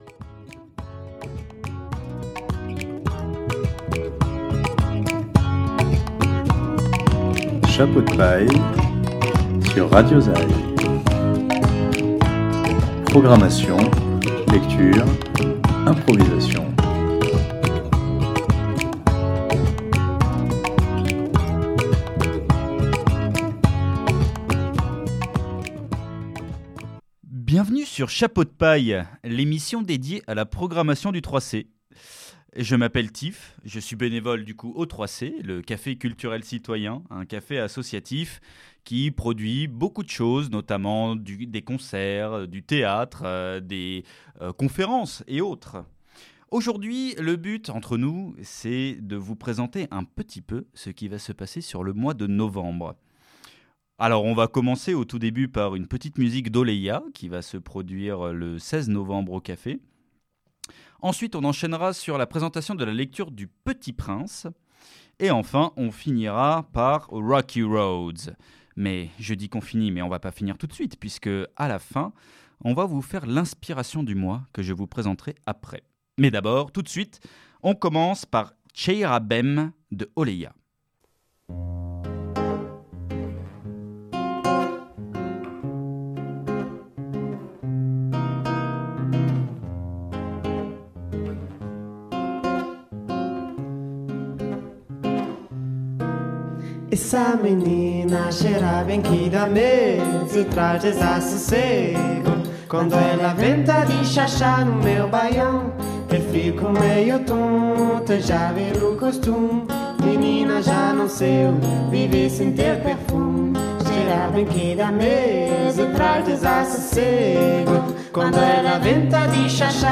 Au menu, la programmation du café pour ce mois-ci, avec des interviews d’artistes et des extraits de spectacles à venir !